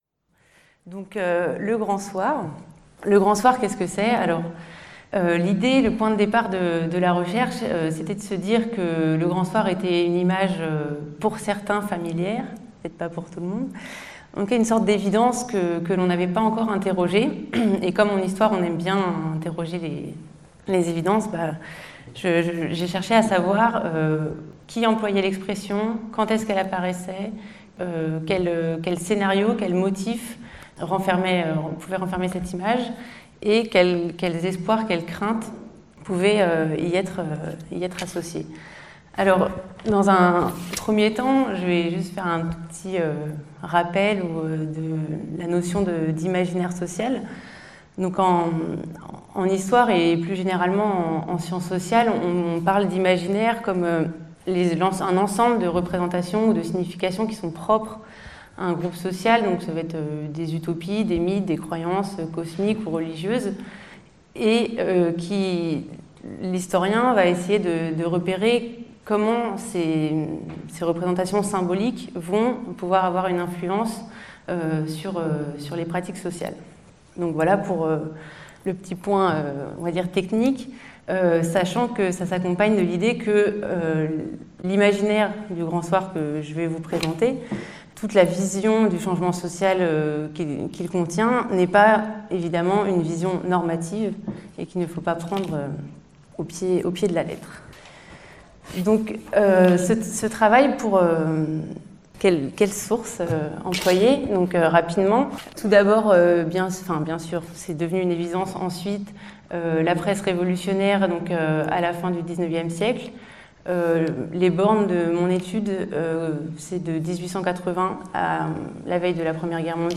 Editions Libertalia | LE GRAND SOIR, mythes révolutionnaires et libertaires - conférence du 31/01/2018 à la Maison de la philosophie à Toulouse.